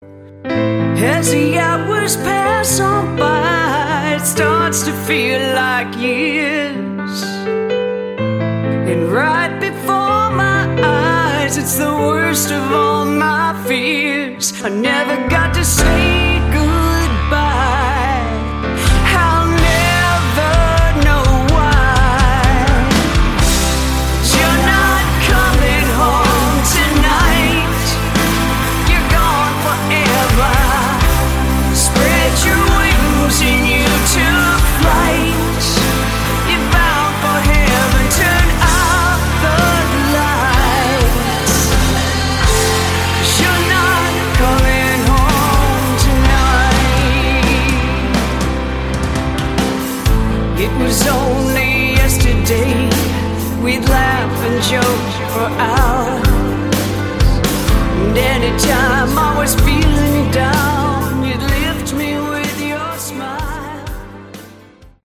Category: Sleaze Glam
vocals
bass
guitars
drums
piano